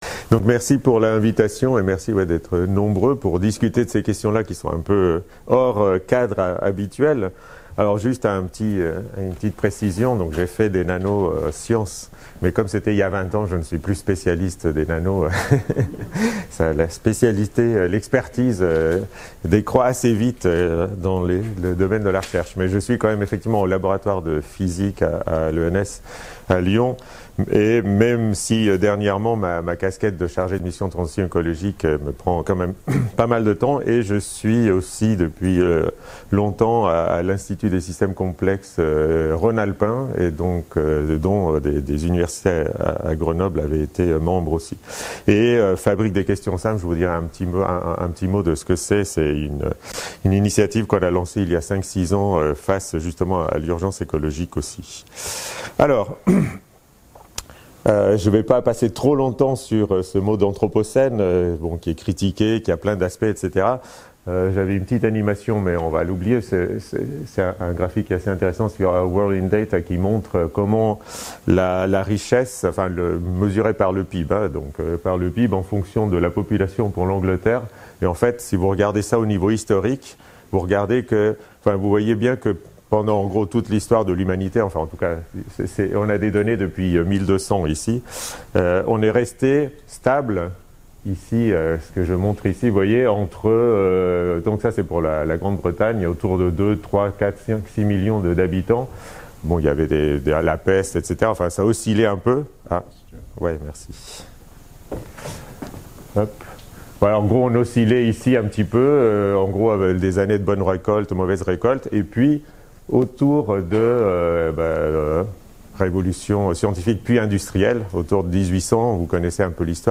Conférence
donnée à Grenoble le 8/12/2023